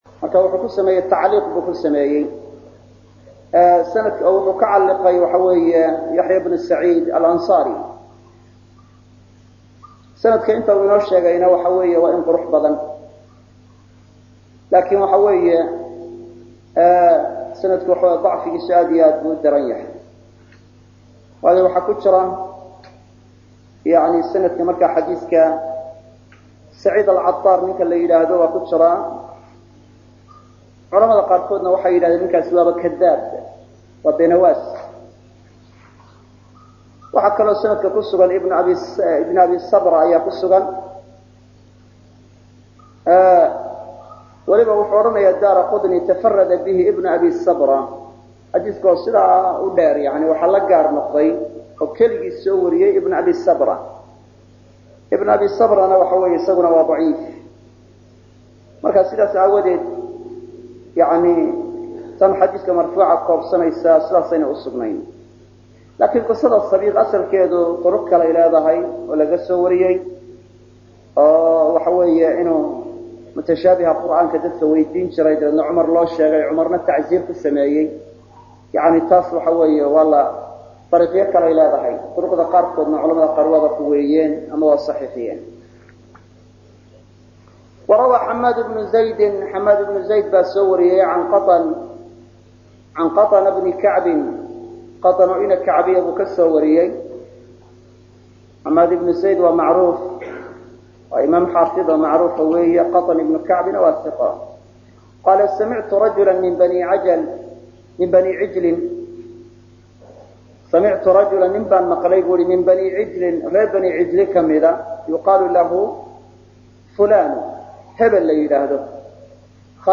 Caqiidatul Salaf Wa Asxaabul Xadiith – Darsiga 12aad - Manhaj Online |